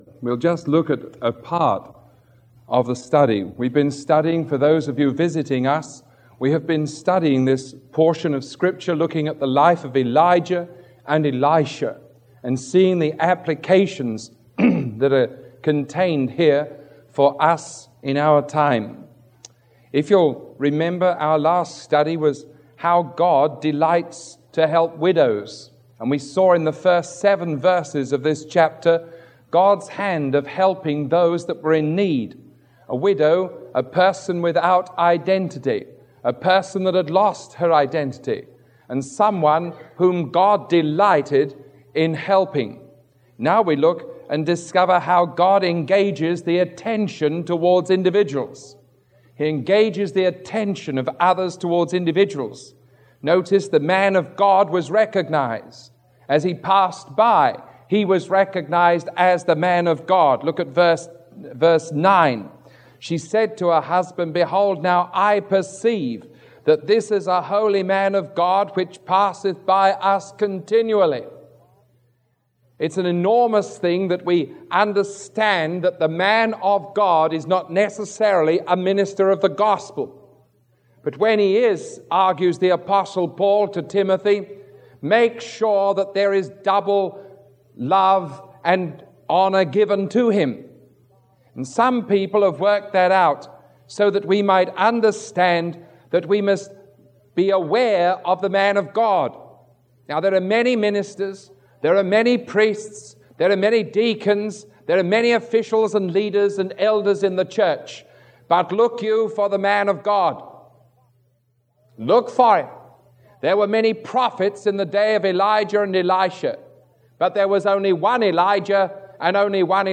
Sermon 0417A recorded on September 13